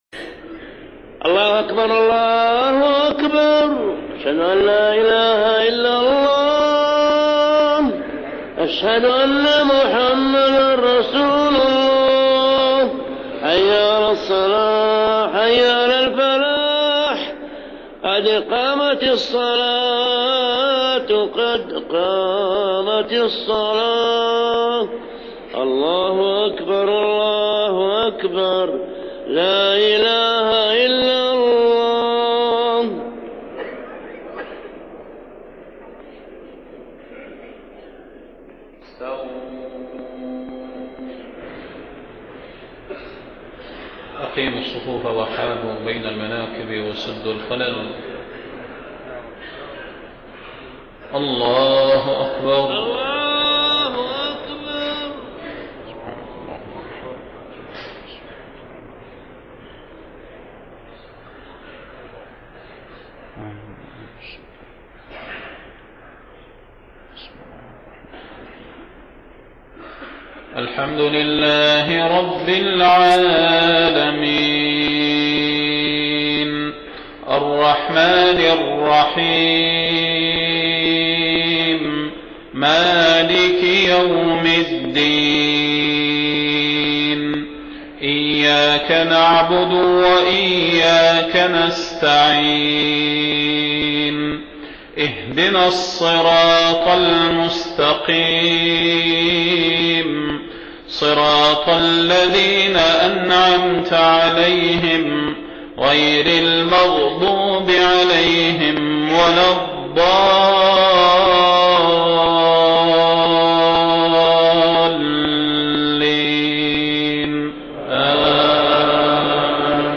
صلاة المغرب 1 محرم 1430هـ من سورة المائدة 51-56 > 1430 🕌 > الفروض - تلاوات الحرمين